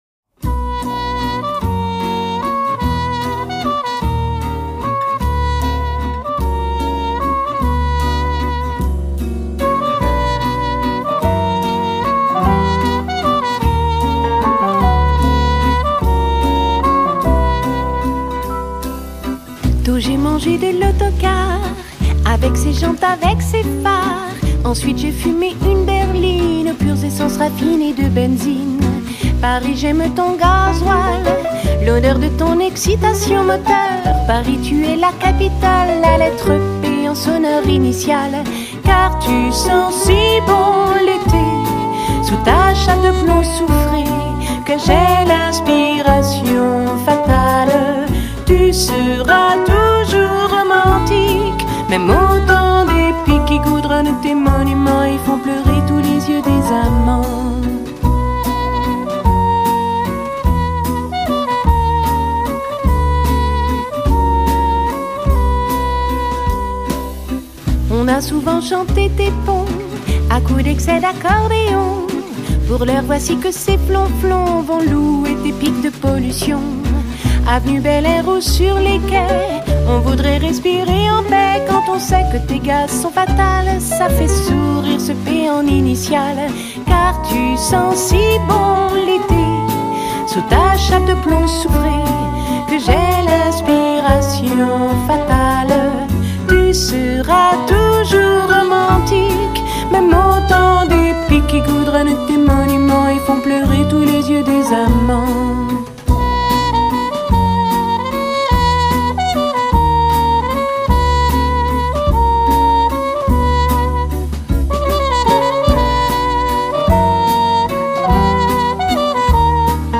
Genre: Pop | Chanson | World